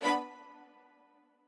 strings5_12.ogg